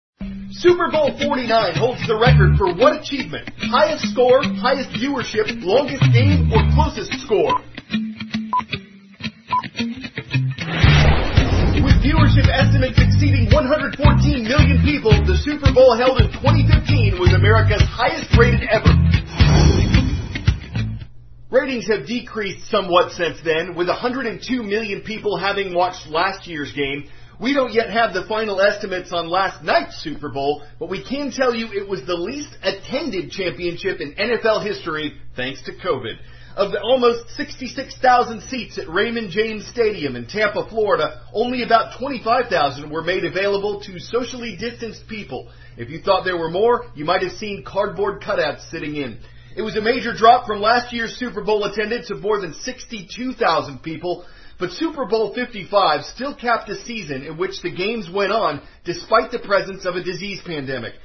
美语听力讲解:新冠疫情影响下 超级碗现场坐满纸片人